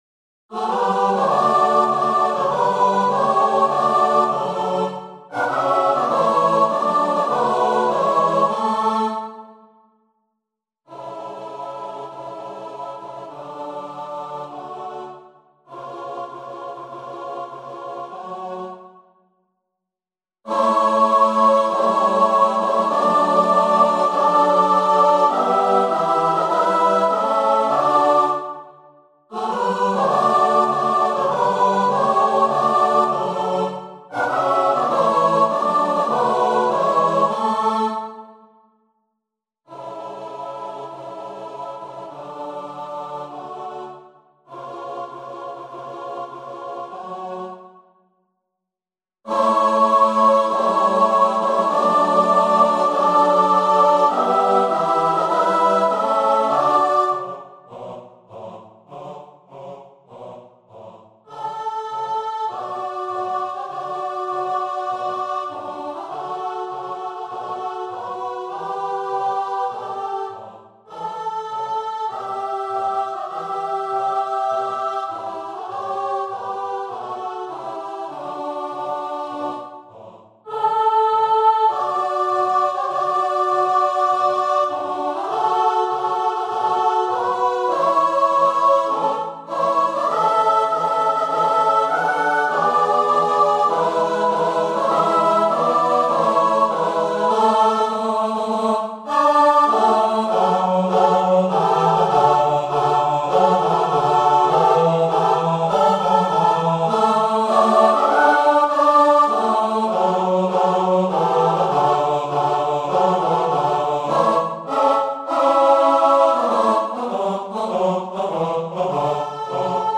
Free Sheet music for Choir (SATB)
Allegro con fuoco non forza (View more music marked Allegro)
4/4 (View more 4/4 Music)
Choir  (View more Easy Choir Music)
Classical (View more Classical Choir Music)